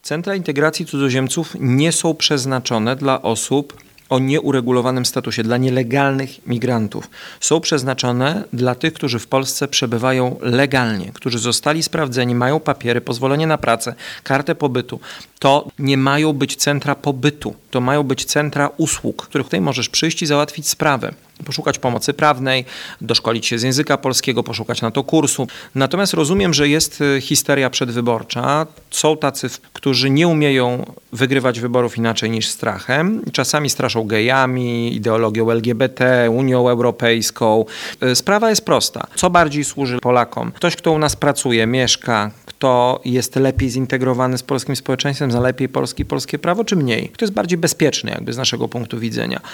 Do sprawy w rozmowie z Twoim Radiem odniósł się marszałek Sejmu Szymon Hołownia, nie kryjąc oburzenia: